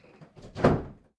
BagajnikDoorsClose.ogg